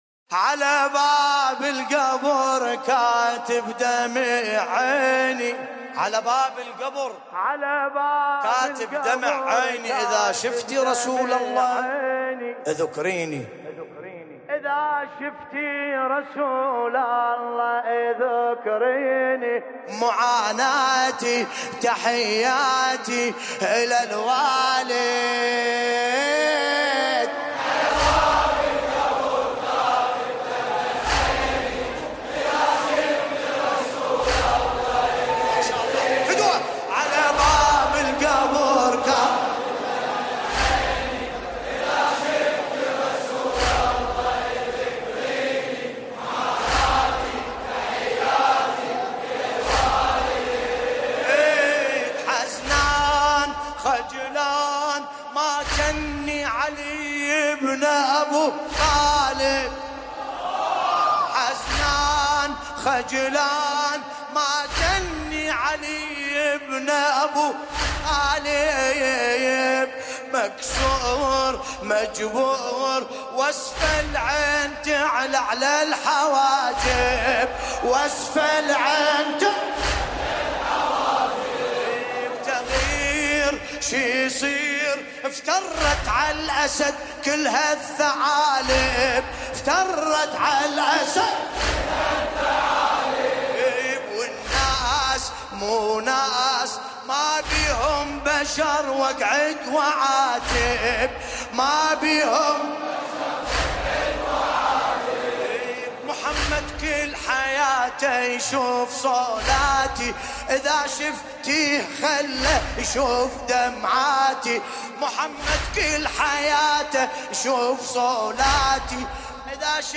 ساحة الشهداء المركزية - البصرة